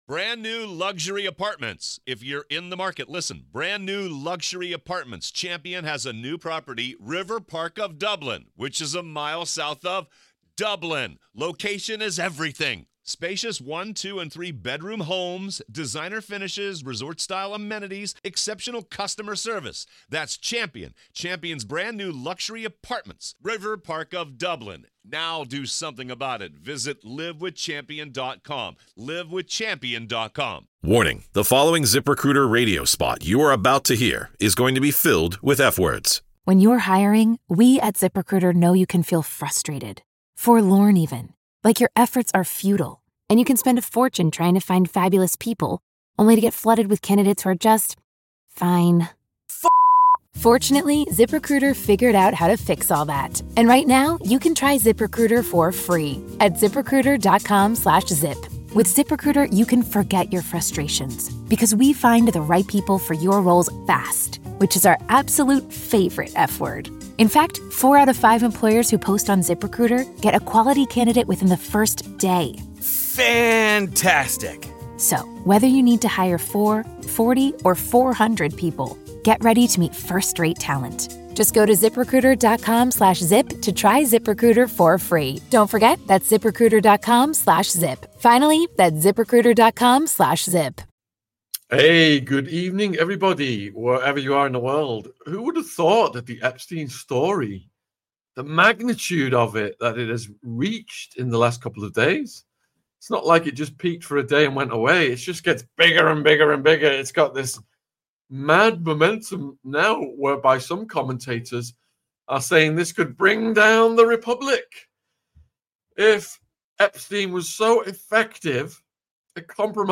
Epstein CoverUp - LIVE CALL IN SHOW | AU 438